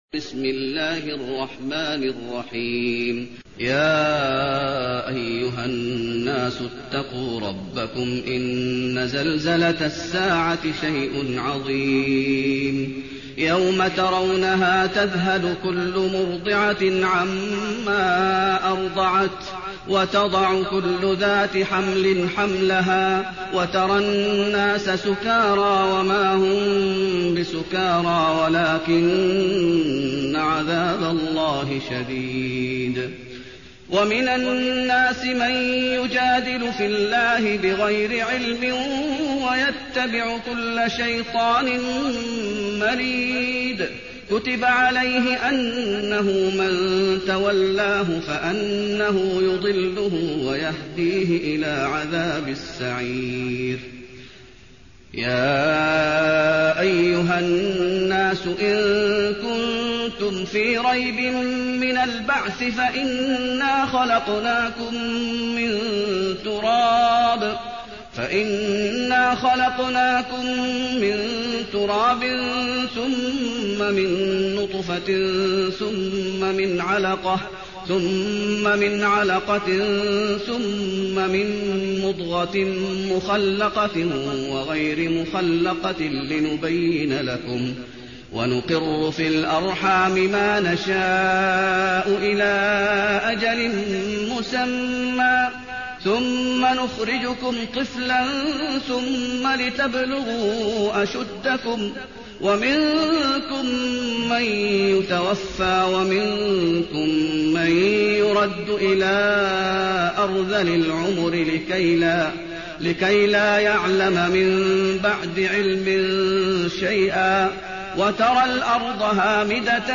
المكان: المسجد النبوي الحج